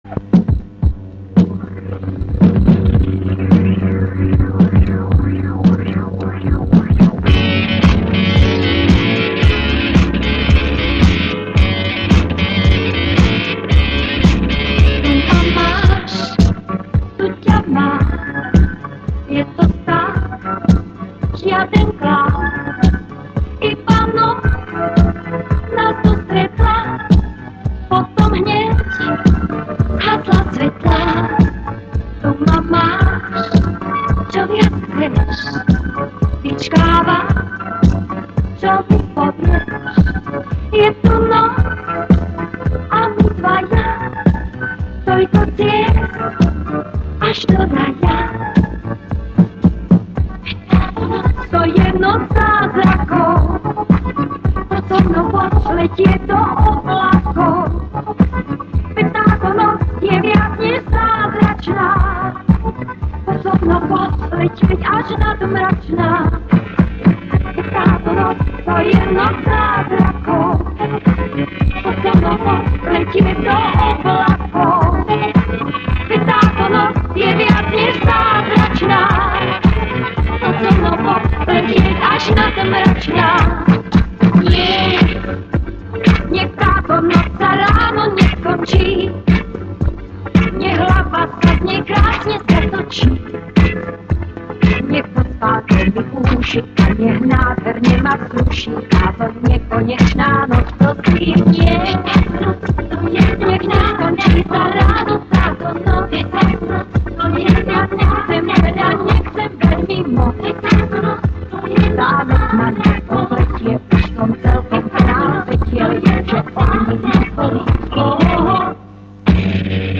ТВ версия